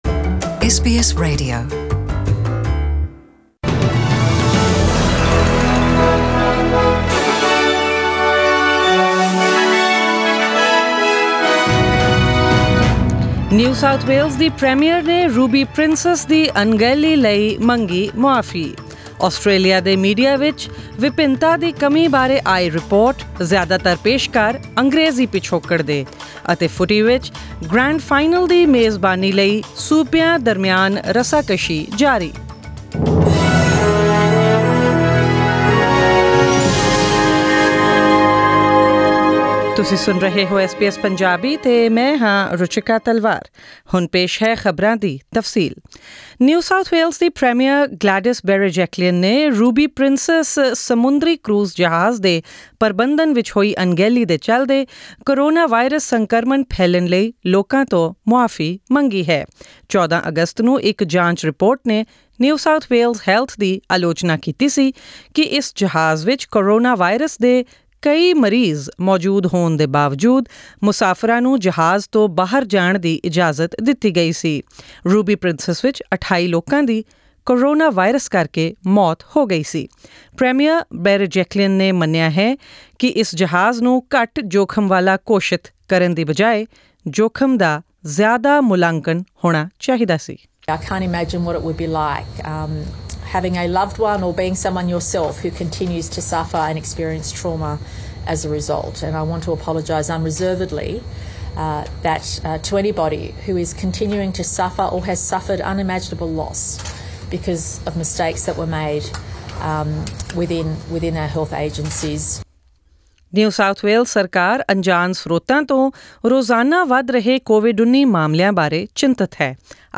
Australian News in Punjabi: 17 August 2020